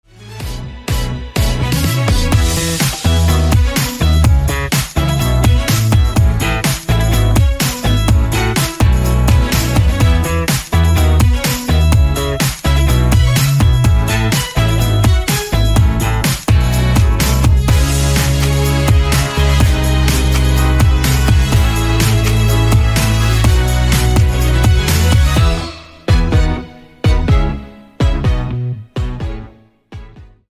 Tono de llamada